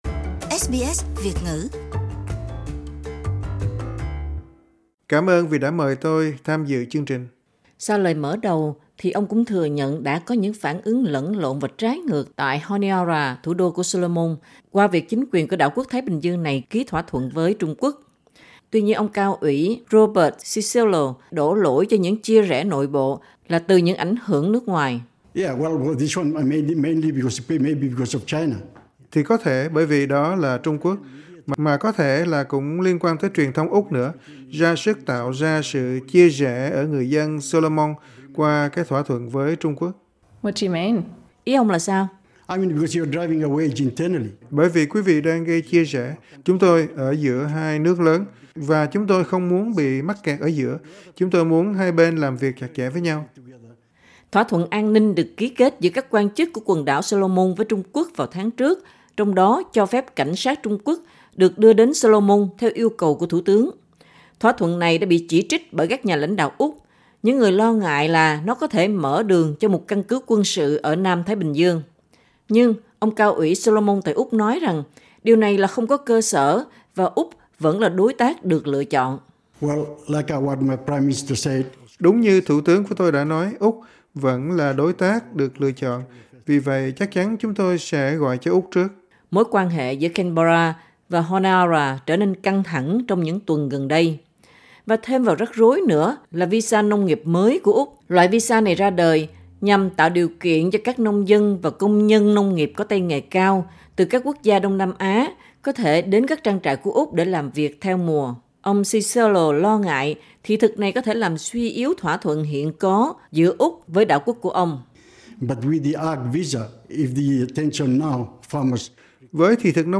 Cao Ủy Solomon tại Úc cho biết căng thẳng giữa Úc và Trung Quốc đang khiến người dân Quần đảo Solomon bị chia rẽ. Trong một cuộc phỏng vấn với SBS News, viên Cao Ủy cũng nêu lên lo ngại rằng thị thực nông nghiệp mới của Úc mở rộng ra các người lao động nông nghiệp từ Đông Nam Á, sẽ ảnh hưởng số lượng lao động từ Solomon vào Úc.